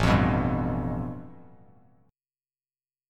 A7sus2#5 chord